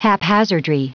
Prononciation du mot haphazardry en anglais (fichier audio)